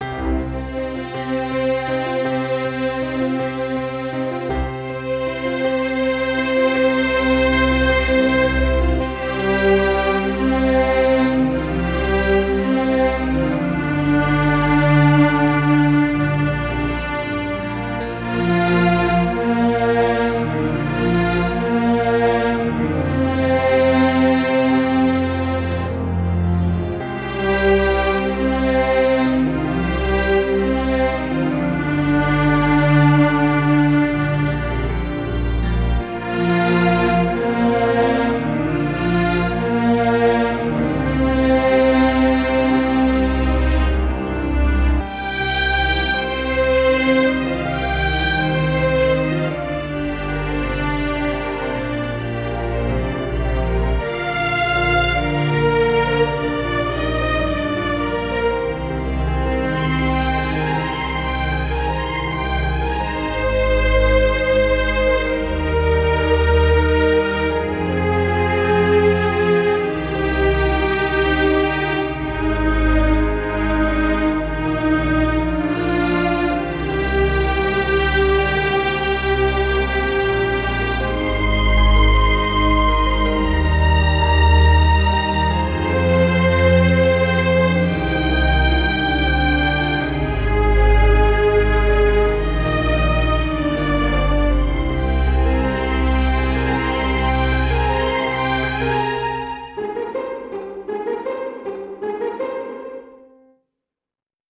para Cordas e Piano
● Violino I
● Violino II
● Viola
● Violoncelo
● Contrabaixo